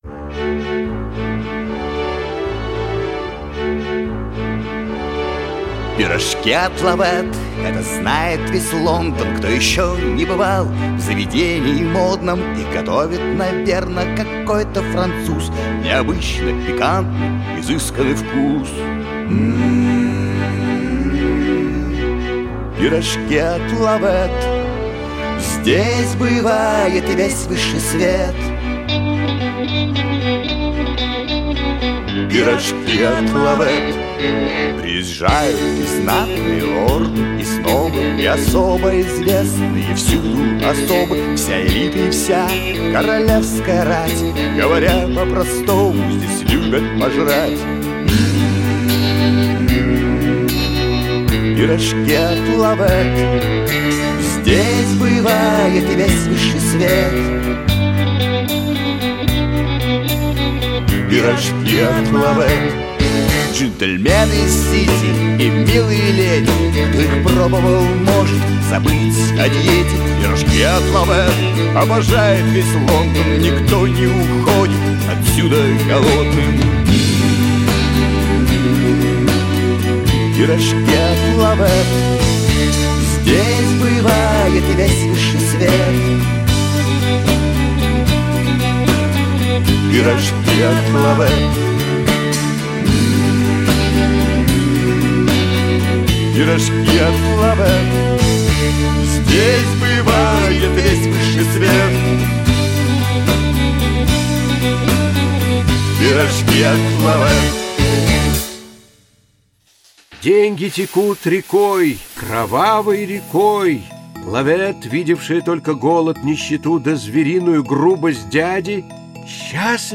Жанр: Жанры / Рок